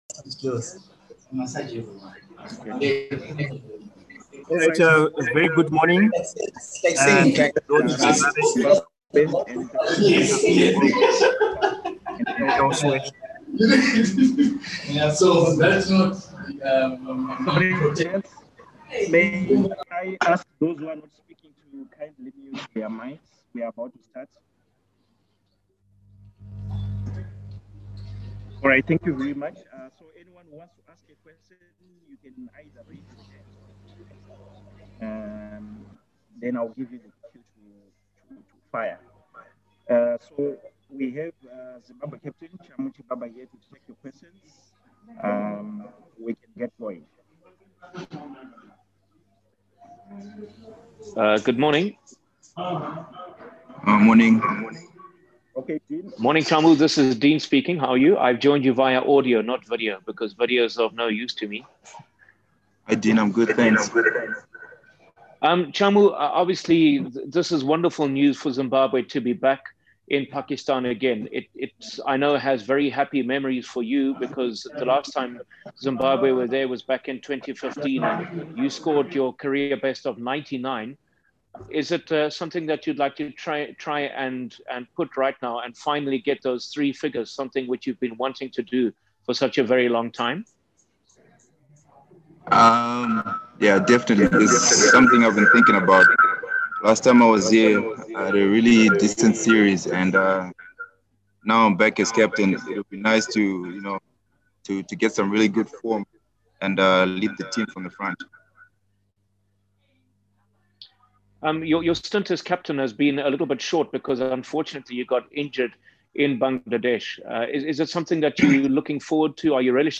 Zimbabwe captain Chamu Chibhabha held pre-series virtual media conferences with the local media today. The three-match ODI series between the two teams, which is part of ICC Men’s Cricket World Cup Super League, begins on Friday.